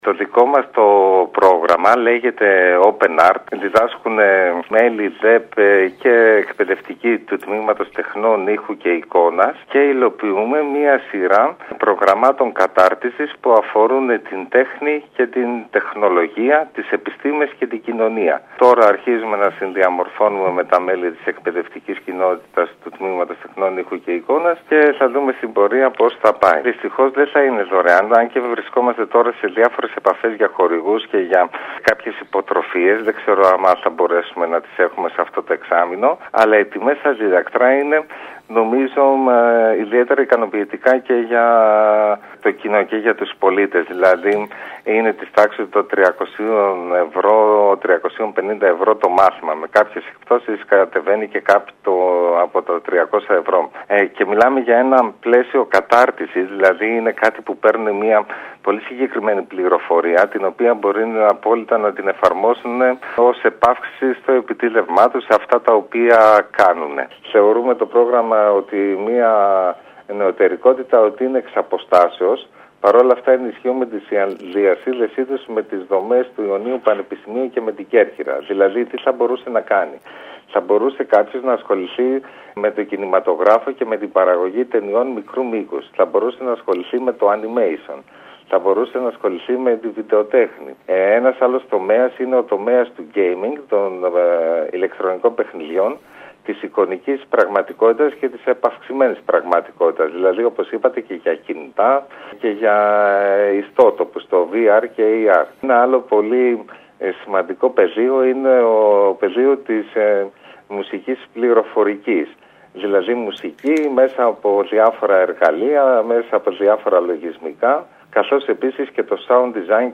Ακούμε τον αναπληρωτή καθηγητή